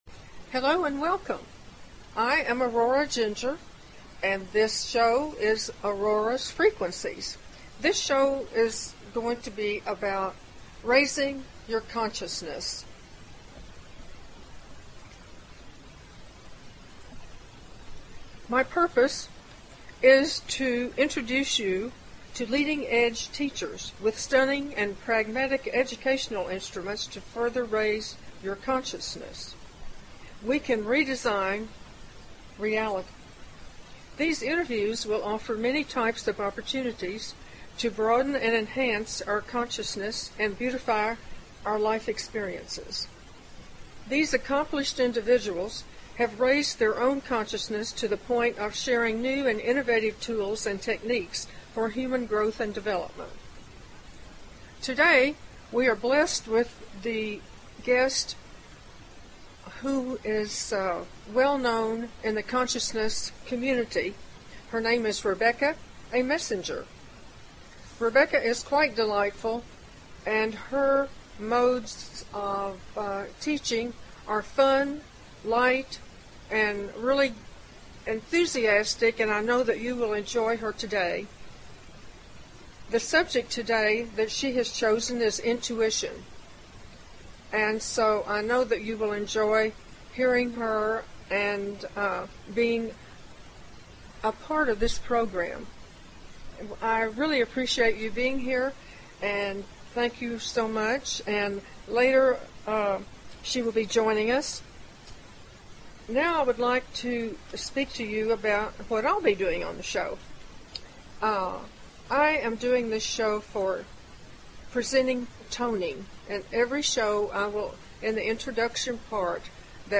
Talk Show Episode, Audio Podcast, Auroras_Frequencies and Courtesy of BBS Radio on , show guests , about , categorized as
These interviews will offer many types of opportunities to broaden and enhance our consciousness and beautify our life experiences.